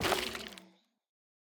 Minecraft Version Minecraft Version latest Latest Release | Latest Snapshot latest / assets / minecraft / sounds / block / sculk / break9.ogg Compare With Compare With Latest Release | Latest Snapshot
break9.ogg